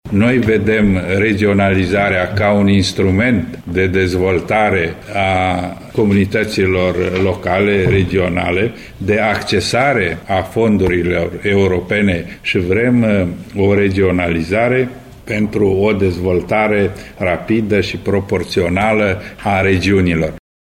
Referitor la regionalizare, senatorul PPDD Simion Purec a afirmat că formaţiunea din care face parte îşi doreşte ca aceasta să se realizeze pe criterii economice şi geostrategice, nu pe criterii etnice: